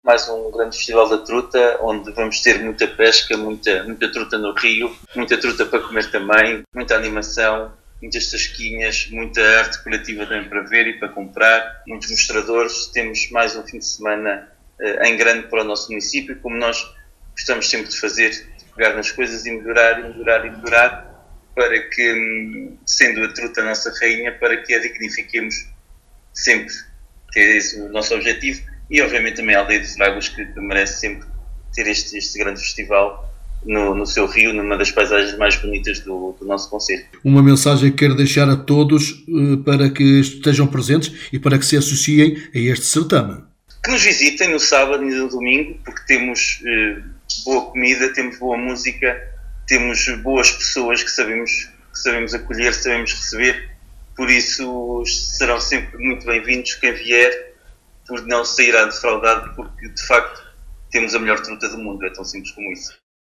Paulo Marques, Presidente do Município de Vila Nova de Paiva, fala deste certame e deixa o convite a quem se queira associar.